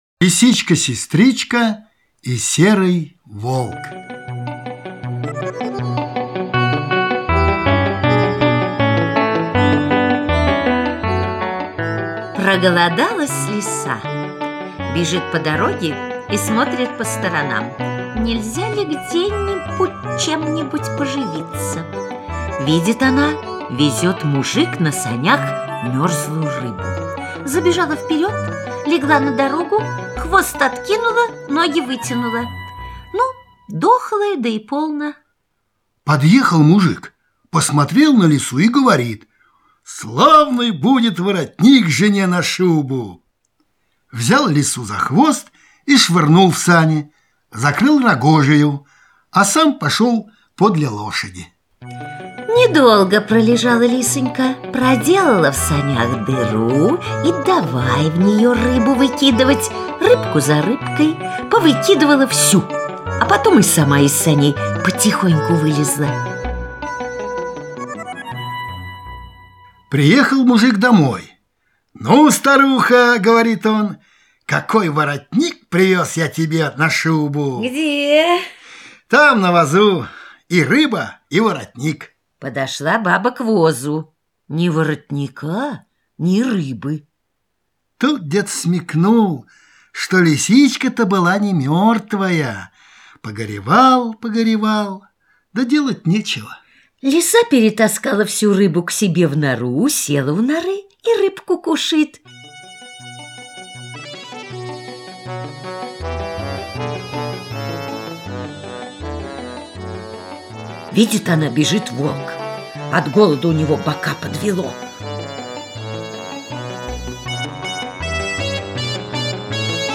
Аудио-сказка "Лисичка-сестричка и серый волк" (скачать и слушать оналайн)
Малыши с удовольствием прослушают русскую народную сказку "Лисичка сестричка и волк", потанцуют под веселые хороводные и плясовые мелодии, сыгранные на звончатых гуслях.